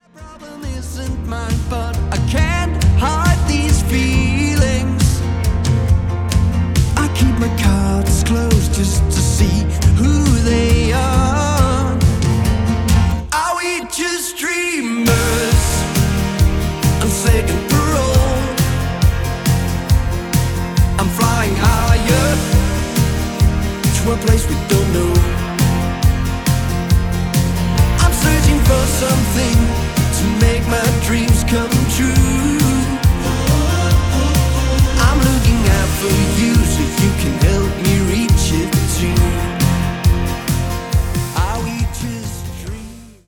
• Folk
• Singer/songwriter
Guitar